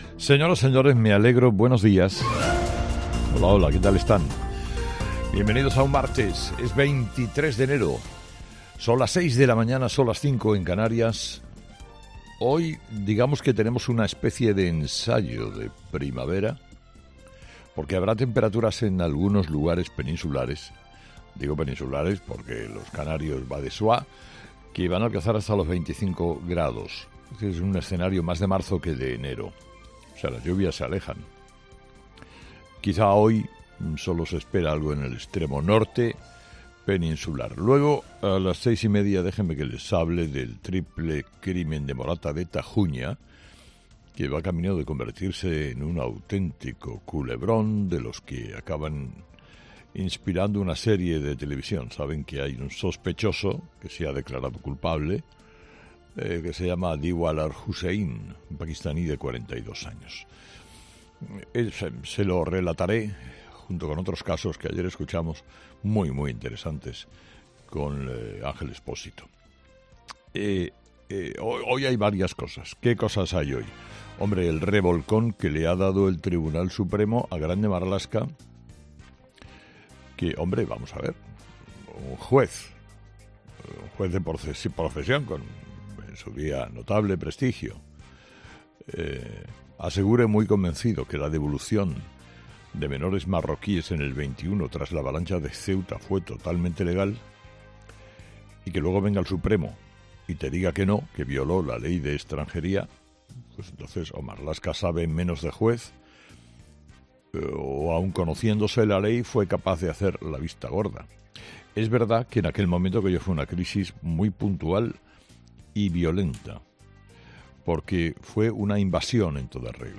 Carlos Herrera, director y presentador de 'Herrera en COPE', comienza el programa de este martes analizando las principales claves de la jornada que pasan, entre otras cosas, por la nueva portavoz del PSOE, Esther Peña, defendiendo la amnistía.